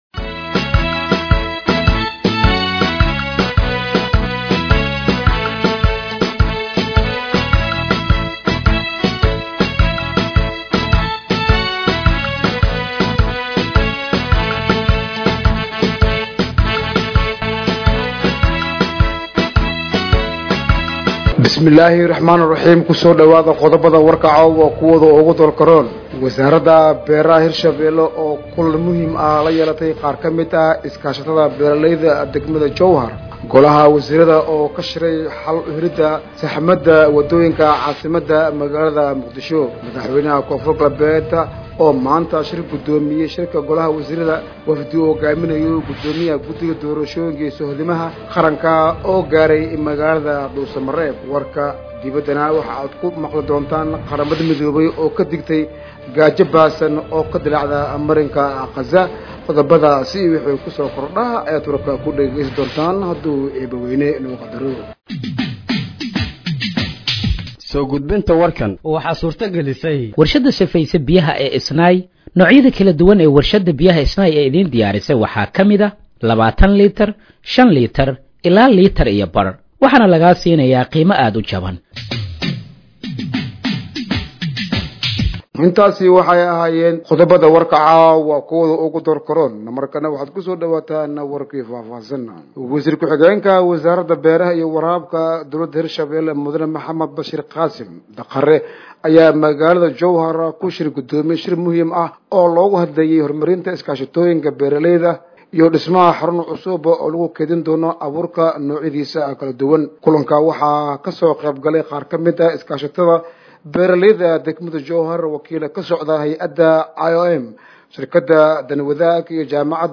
Dhageeyso Warka Habeenimo ee Radiojowhar 24/07/2025
Halkaan Hoose ka Dhageeyso Warka Habeenimo ee Radiojowhar